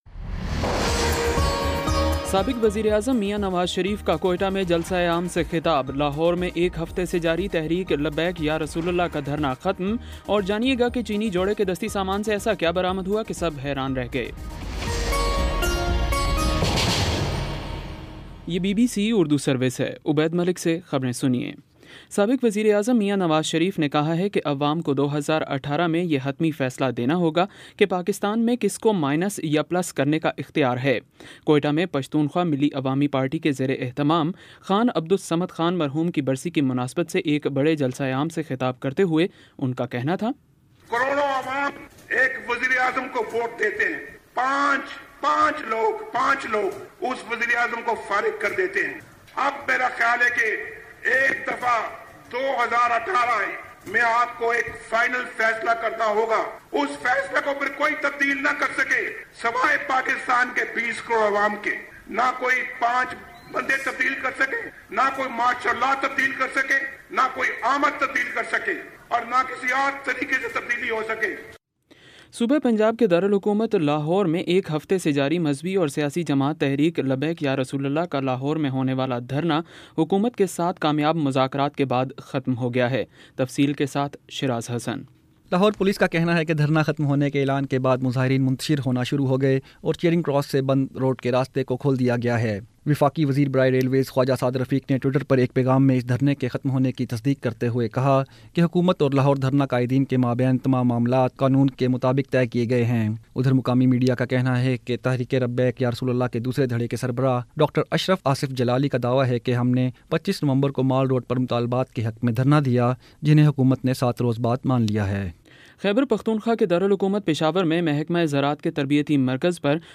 دسمبر 02 : شام پانچ بجے کا نیوز بُلیٹن